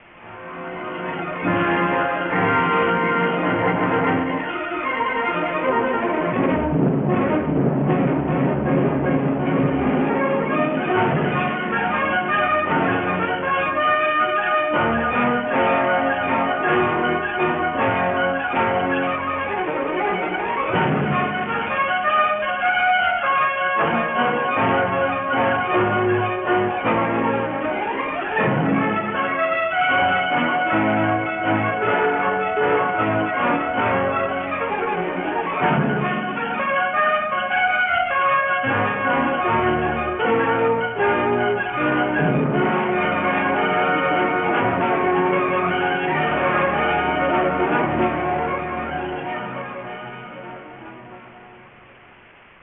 Original Track Music